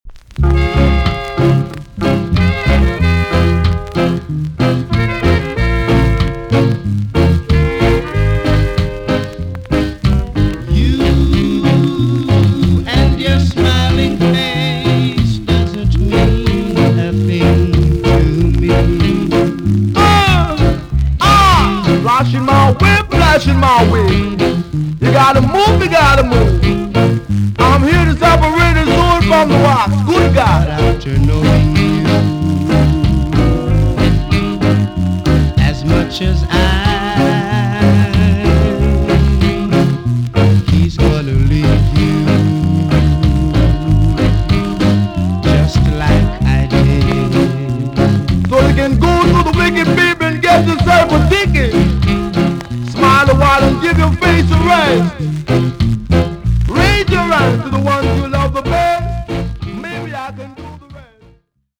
TOP >SKA & ROCKSTEADY
VG+ 少し軽いチリノイズが入ります。
NICE ROCK STEADY DJ CUT TUNE!!